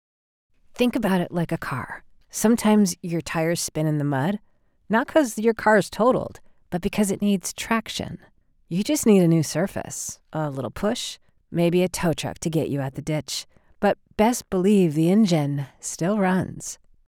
Storyteller